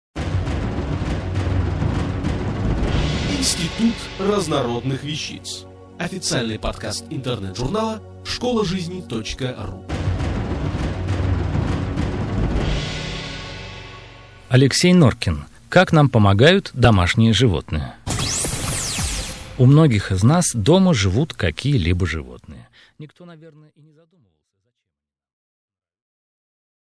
Аудиокнига Как нам помогают домашние животные | Библиотека аудиокниг